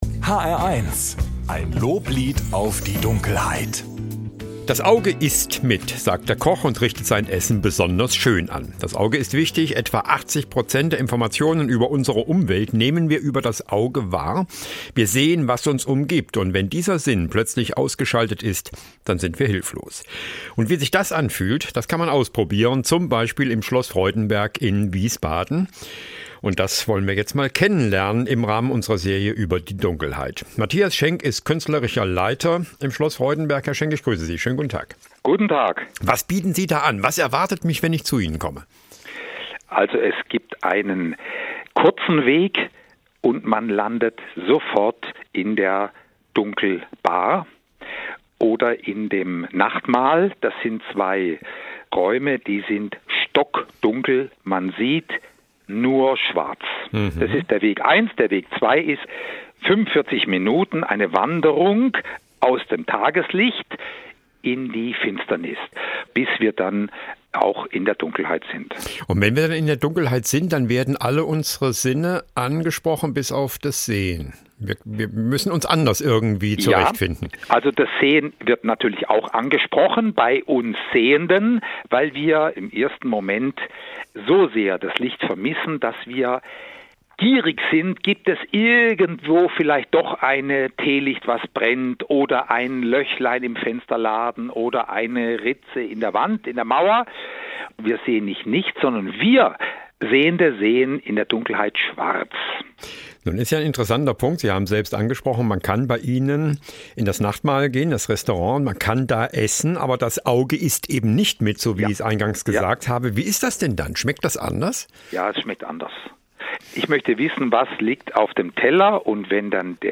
Interview „Lob der Dunkelheit“ hr1 „Lob der Dunkelheit“ 09.